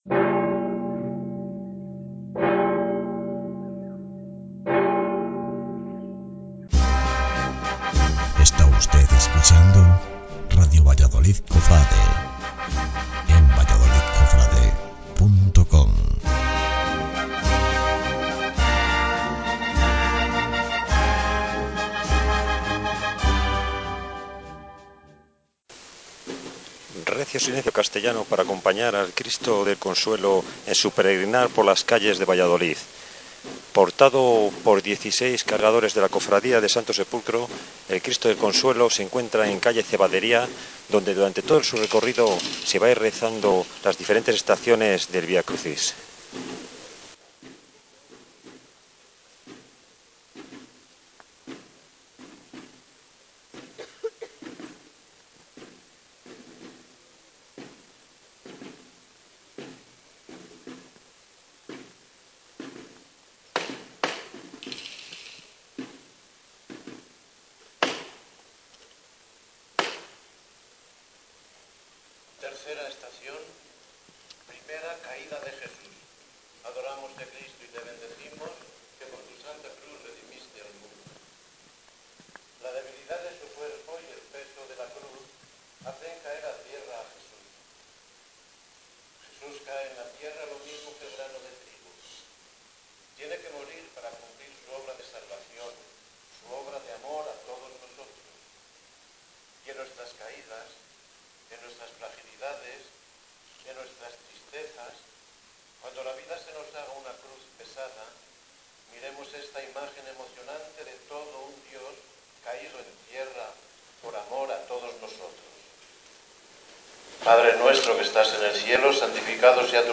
Procesión del Santo Cristo del Consuelo